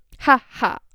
Ha-Ha
annoyed annoying female girl speech spoken talk vocal sound effect free sound royalty free Voices